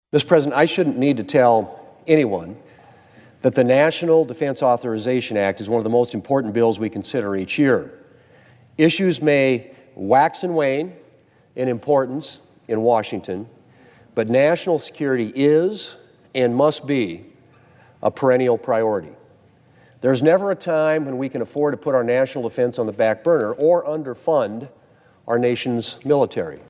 WASHINGTON, D.C.(HubCityRadio)- Earlier this week, Senator John Thune was on the floor of the Senate stressing the importance of getting the National Defense Authorization Act(NDAA) done by the end of this year.